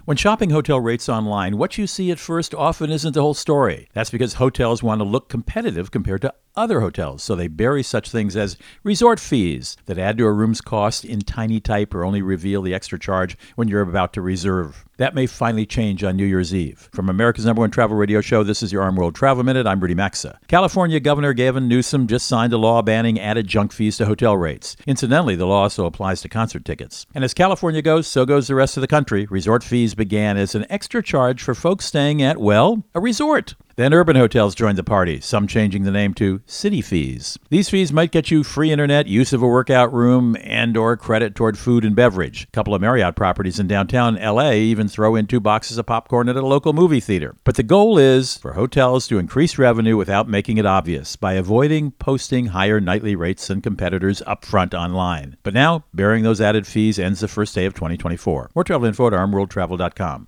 Co-Host Rudy Maxa | Hotel Rate Transparency is Coming